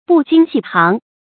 不矜细行 bù jīn xì xíng
不矜细行发音
成语注音ㄅㄨˋ ㄐㄧㄣ ㄒㄧˋ ㄒㄧㄥˊ